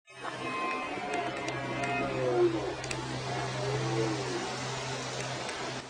Non vraiment je suis comme un enfant, car s est le Brame du cerf qui commence. tout autour de moi resonne du chant de ces etres majestueux
Pour entendre le Brame du cerf C est ici. Par contre s est enregistré avec mon appareil photo num, donc le son n est pas terrible.
brame2.wav